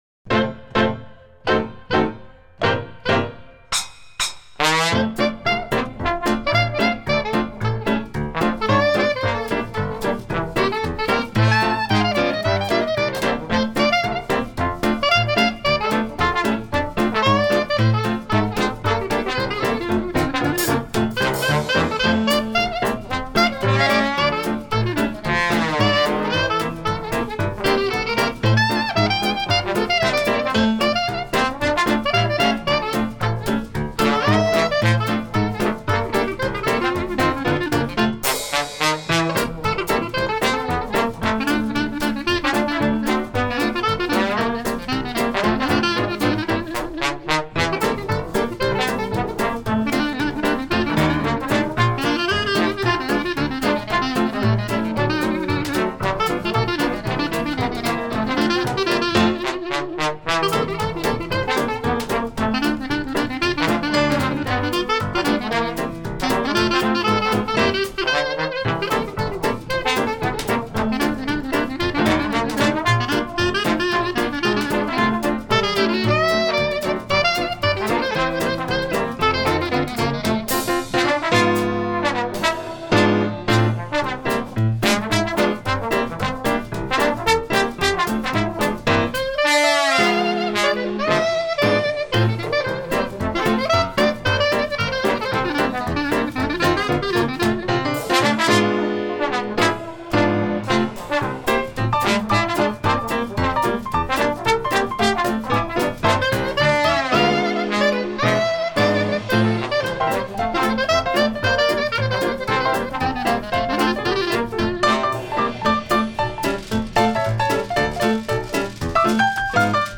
un monument du Jazz New Orleans !
trombone
clarinette
piano